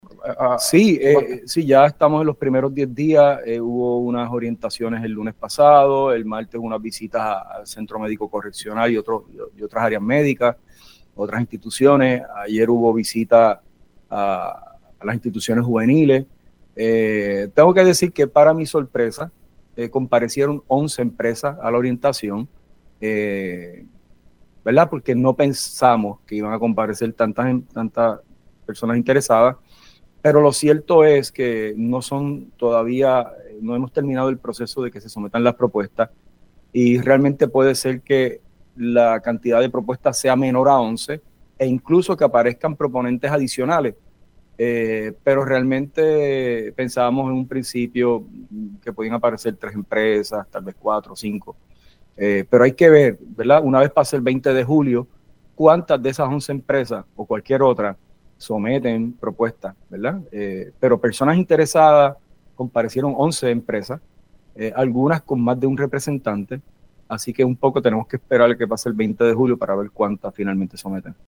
El secretario de Corrección, Francisco Quiñones aseguró en Pega’os en la Mañana que hizo lo correcto al informar sobre la cancelación del contrato de servicios médicos de Physician Correctional.